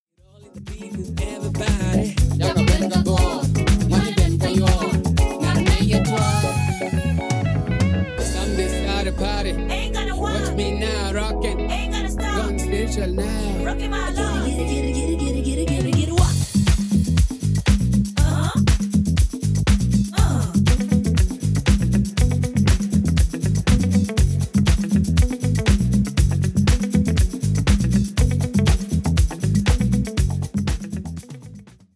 ジャンル(スタイル) DEEP HOUSE / AFRO HOUSE